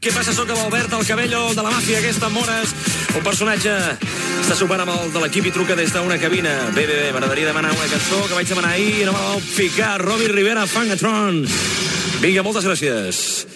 Un oïdor demana una cançó
Musical